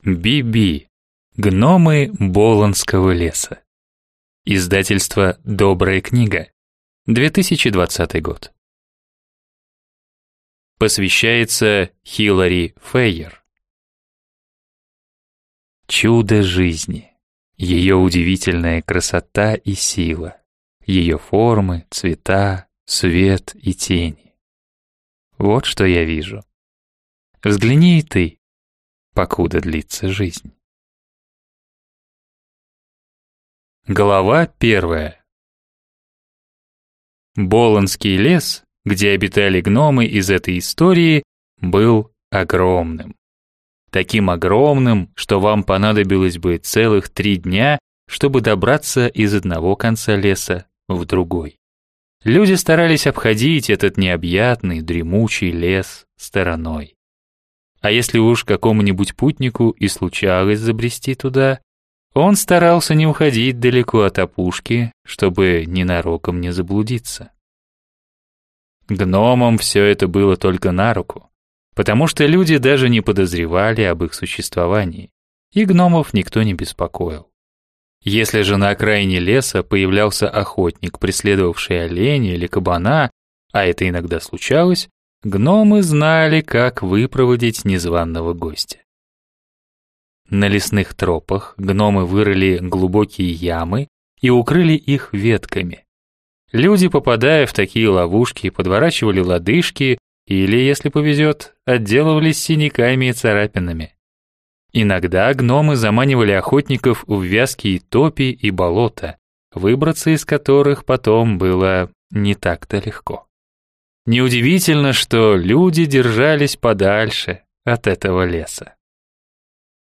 Аудиокнига Гномы Боландского леса | Библиотека аудиокниг
Прослушать и бесплатно скачать фрагмент аудиокниги